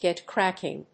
gèt crácking